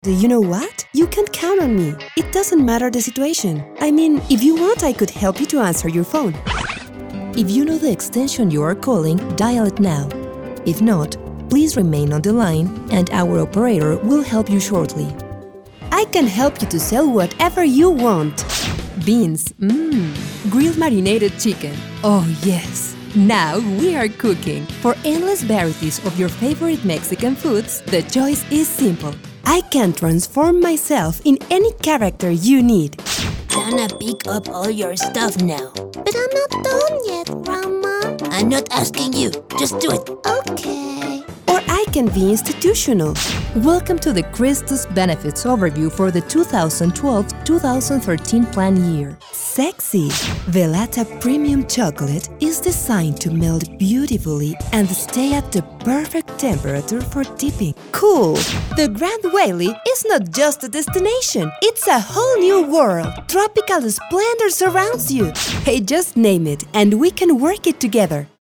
Female
LATAM neutral and Mexican accent. Her voice is authentic, confident, clear and warm. She can also be energetic, happy, girl next door and youthful.
Foreign Language
All our voice actors have professional broadcast quality recording studios.